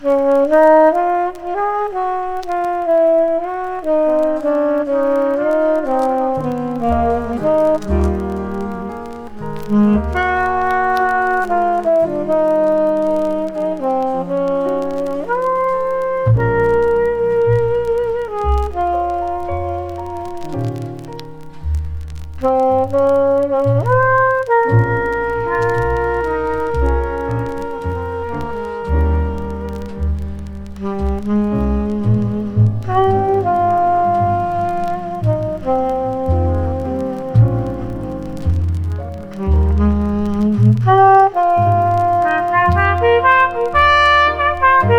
Jazz, Cool Jazz　USA　12inchレコード　33rpm　Mono